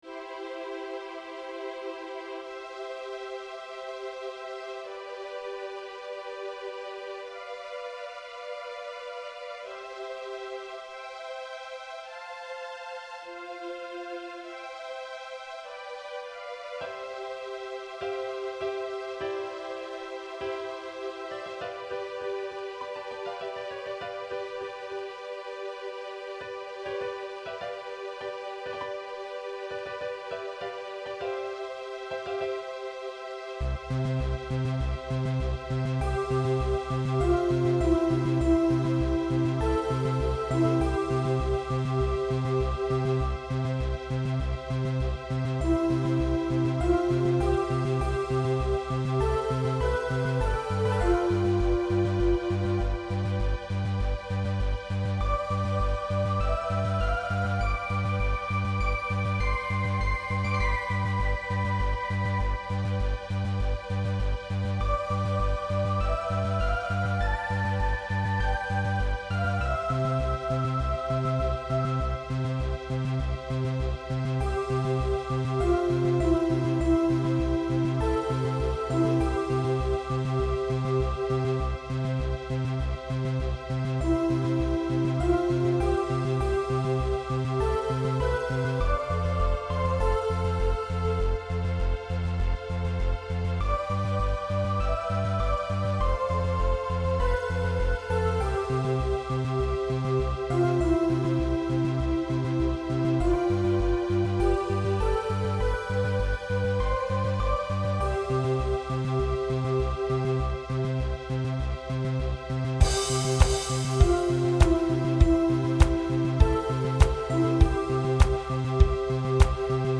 Melodierne fejler for saa vidt ikke noget, men de er helt sikkert ikke godt arrangeret.